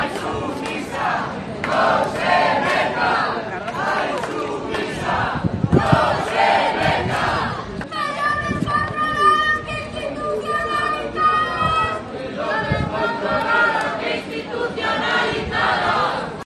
Gritos de la concentración contra el desalojo de la Insumisa